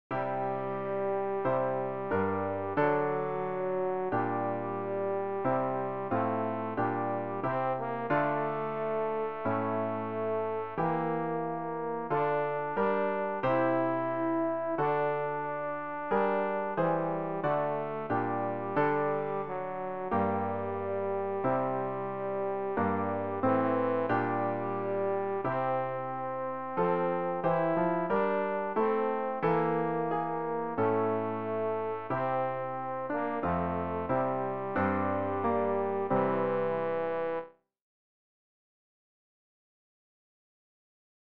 rg-570-lobet-den-herren-alle-tenor.mp3